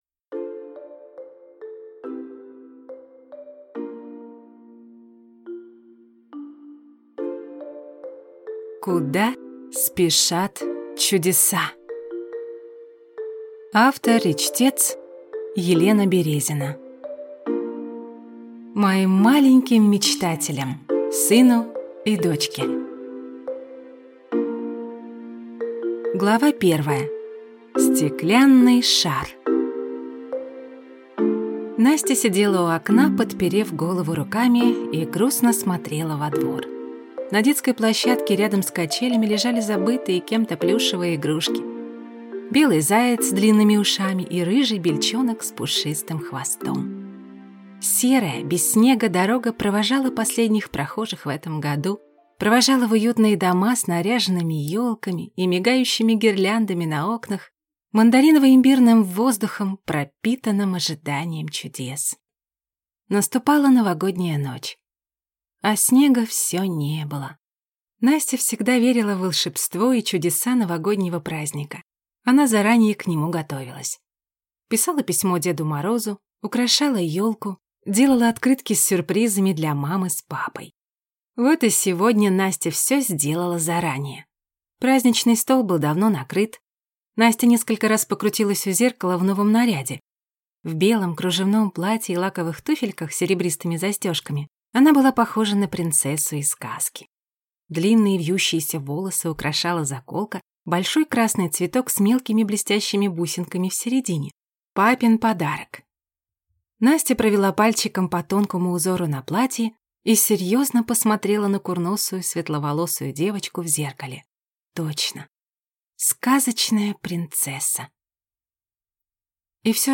Аудиокнига Куда спешат чудеса | Библиотека аудиокниг